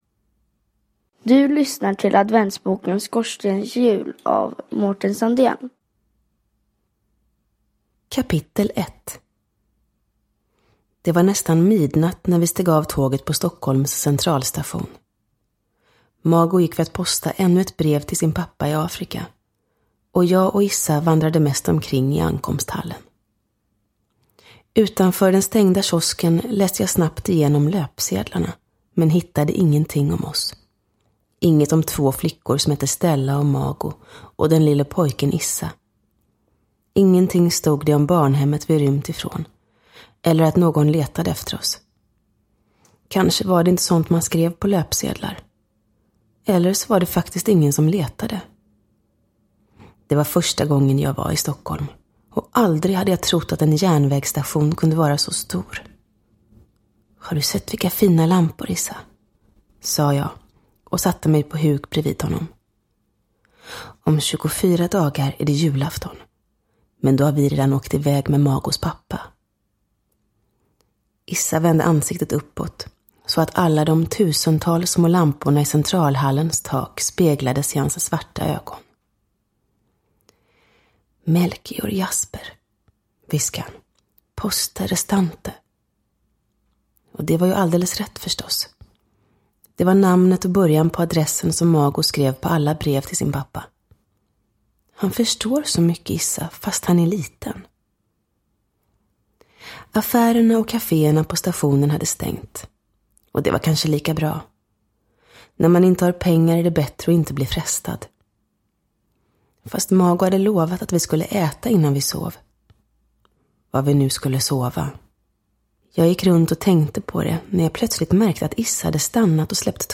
Uppläsare: Julia Dufvenius